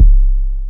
808 - EXSPENSES.wav